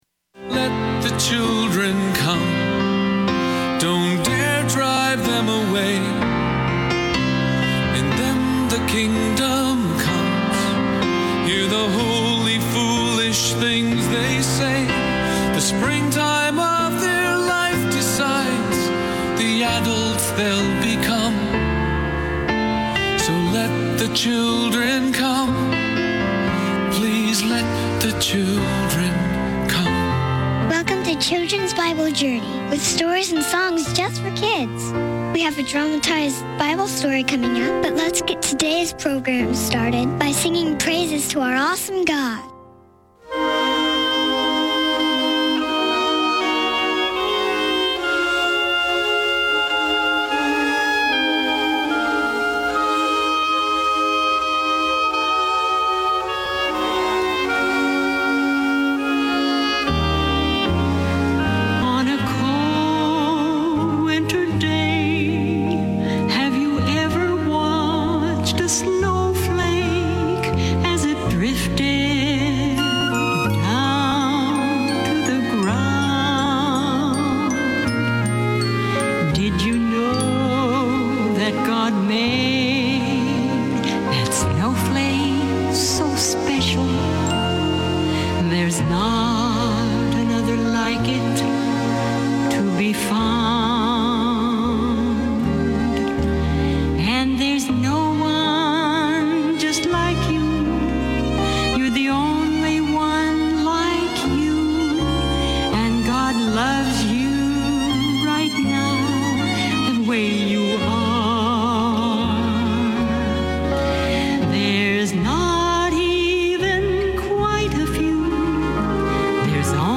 Bedtime Stories